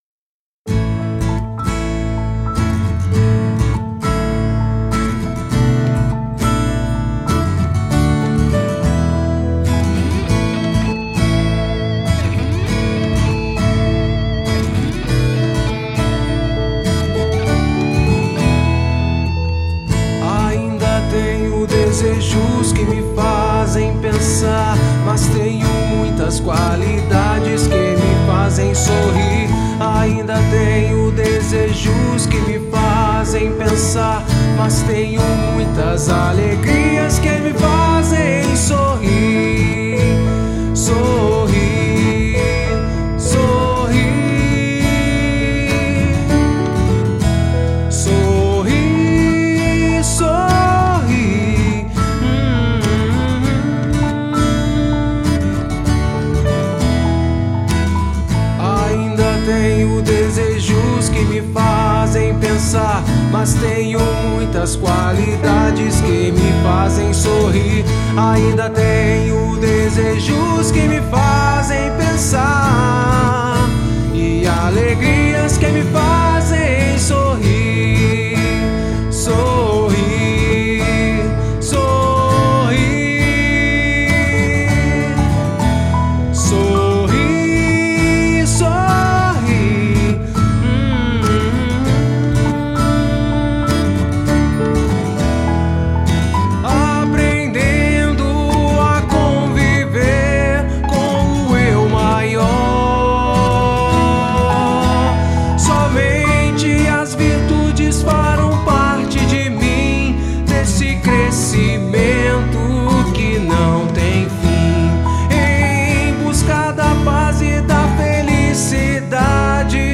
EstiloFolk